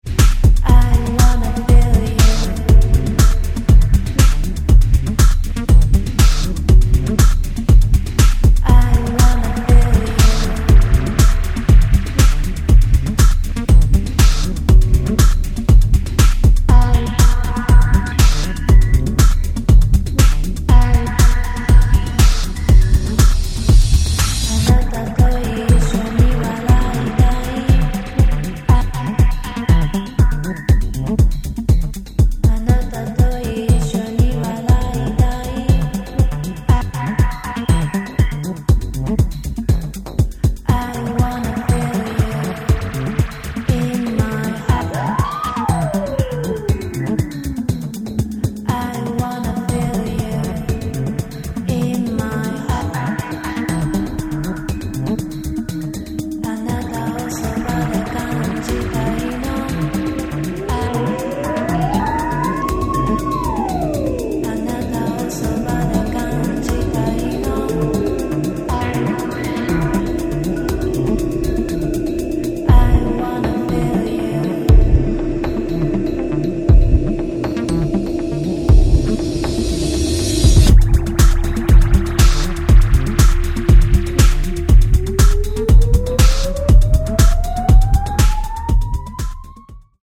ニューウェイヴ/EBM/アシッド・ハウス/トライバル等の要素を退廃的に昇華した、濃厚なデビュー作となっています。